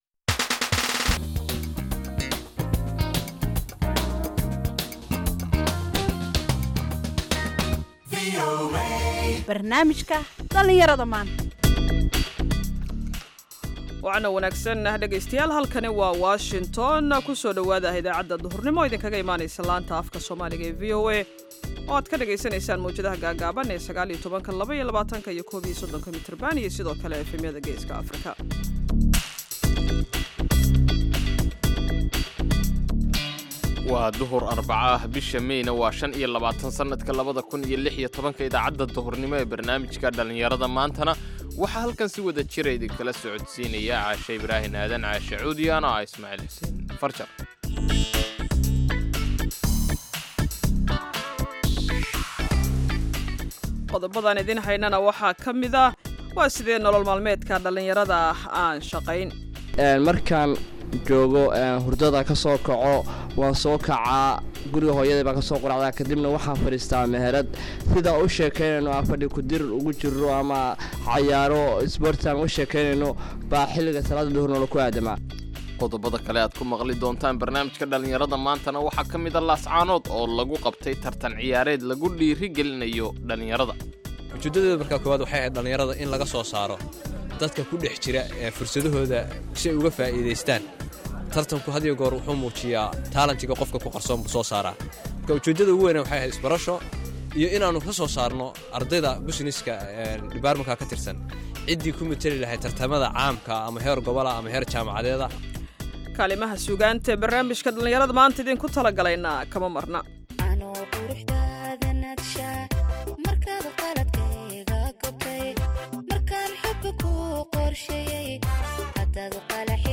Idaacadda Duhurnimo waxaad qeybta hore ku maqli kartaa wararka ugu waaweyn ee Soomaaliya iyo Caalamka. Qeybta danbe ee idaacaddu waxay idiin soo gudbinaysaa barnaamijyo ku saabsan dhalinyarada maanta.